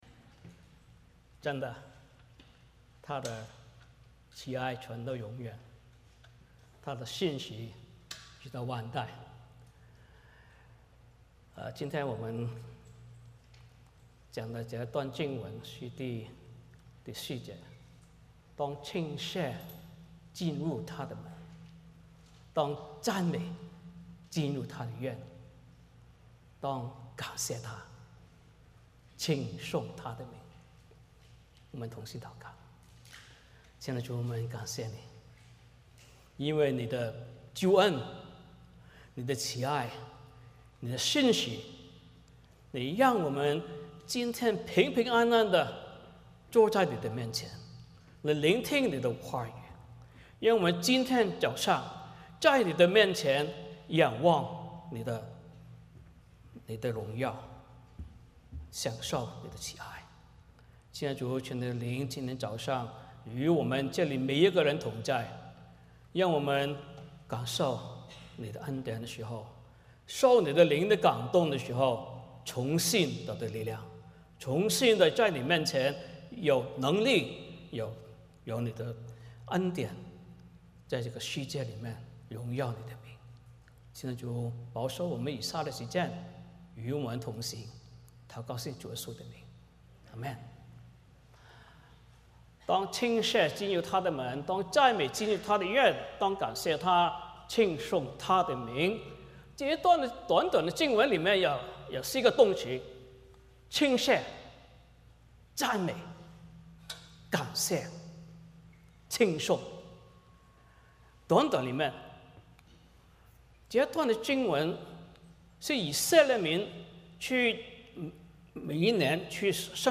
诗篇100 篇 Service Type: 主日崇拜 欢迎大家加入我们的敬拜。